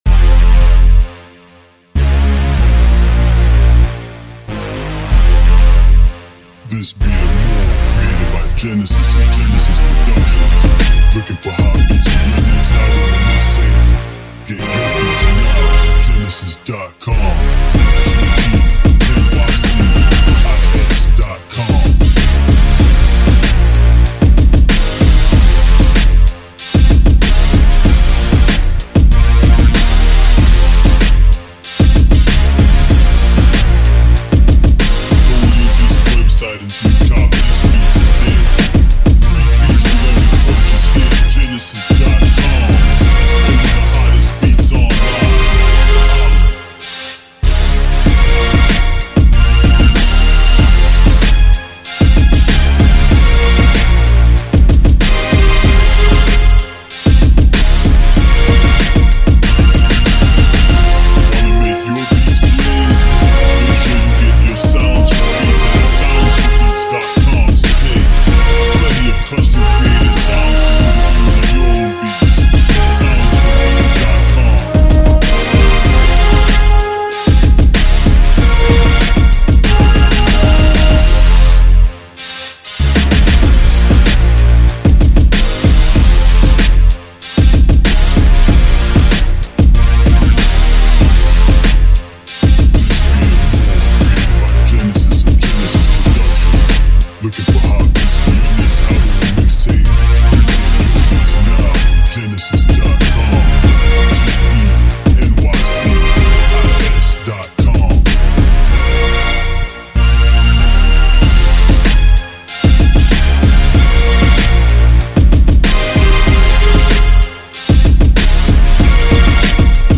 Instrumental Style Beat